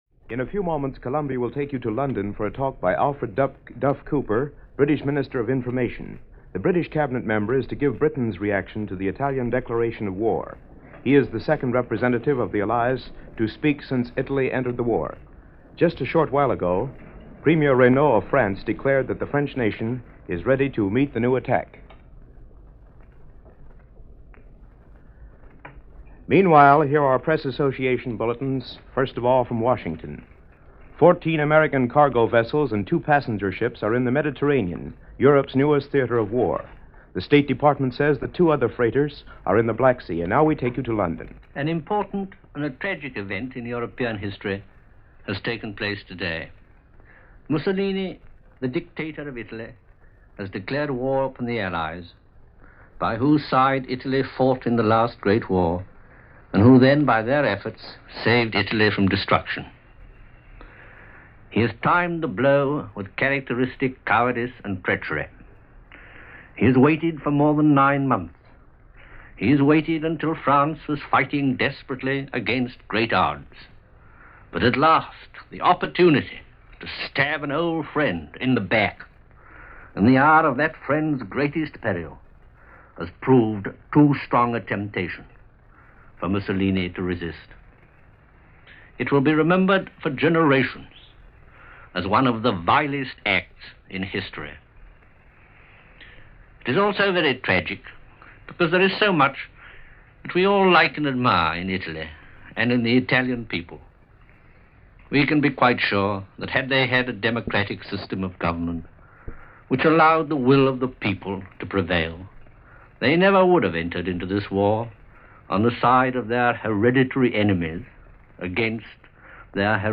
Here is that address by Minister of Information Alfred Duff Cooper as it was given over the BBC World Service on June 10, 1940.